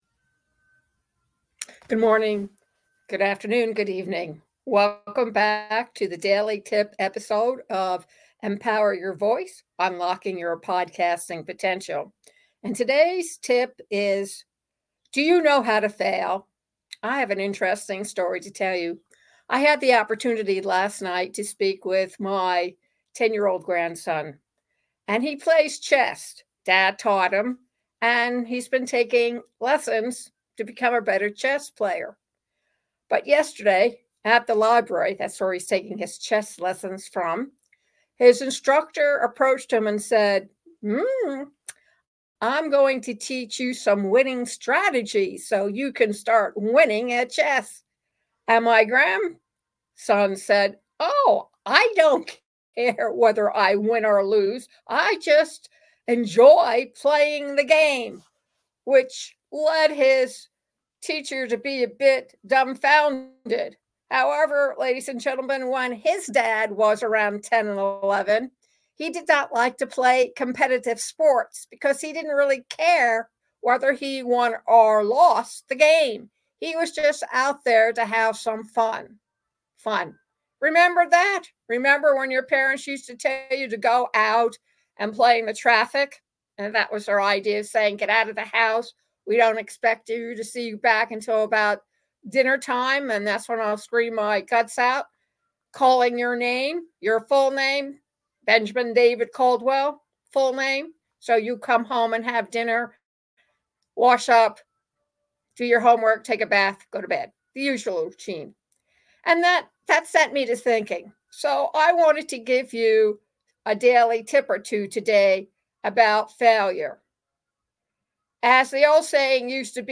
audio commentary